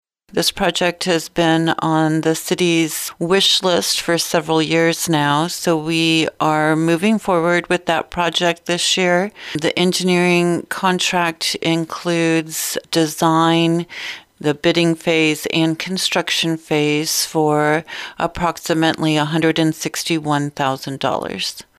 This is for work that will take place on International Road.  City Administrator Roze Frampton explains.